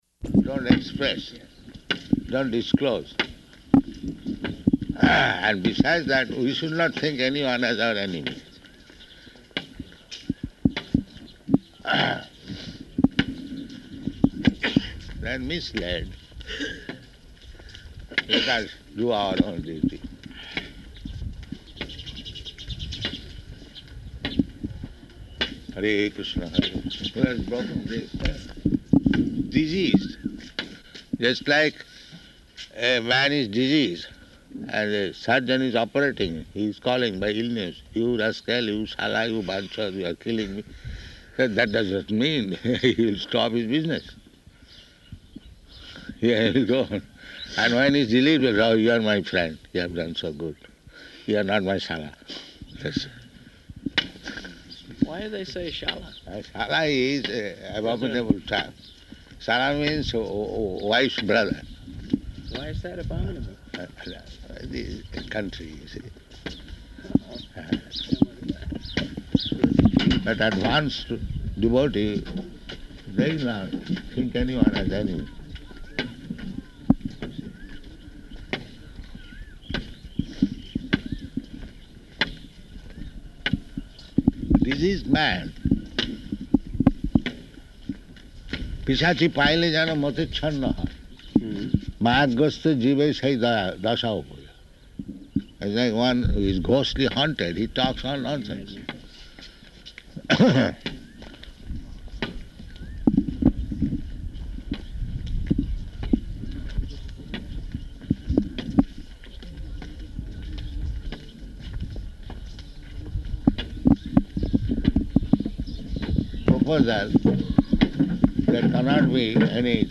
Morning Walk
Type: Walk
Location: Māyāpur